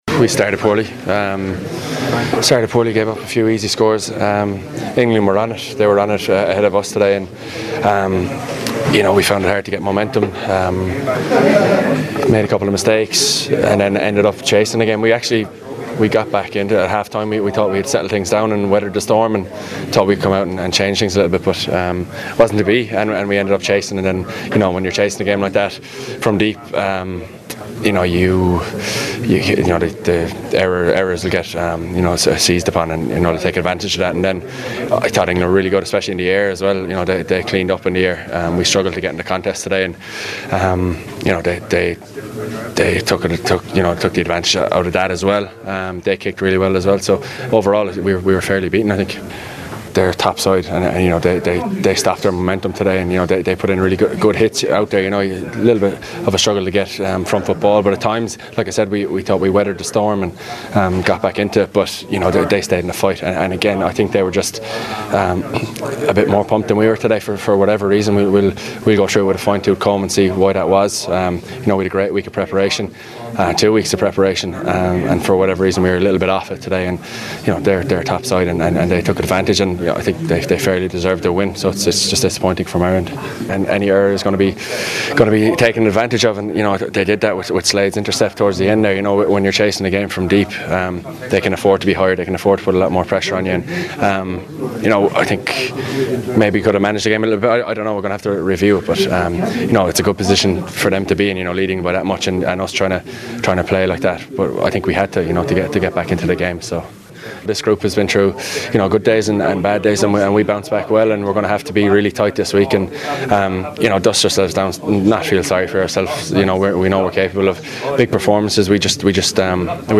Irish scrum-half Conor Murray spoke after the game…